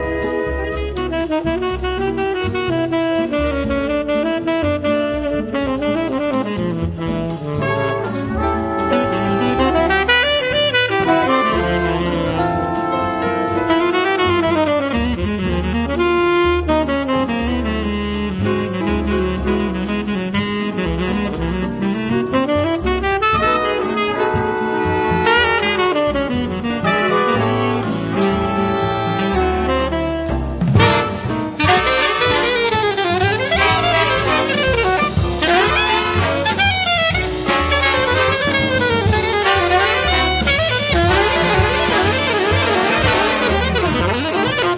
The Best In British Jazz
Recorded Curtis Schwartz Studios, Sussex January 13 2004
and the whole ensemble rockets along.